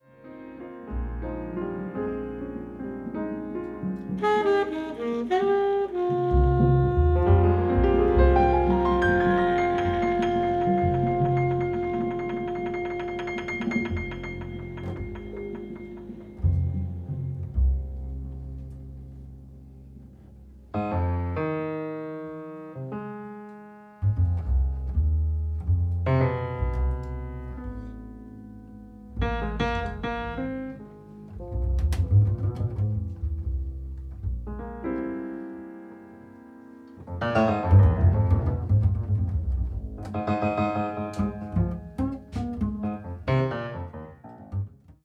Recorded on 7th June 2017 at Jazz Spot Candy
だからこの録音当日も何も決めないで始めた。
「その場作曲による即興」たどりついた境地がこれだ。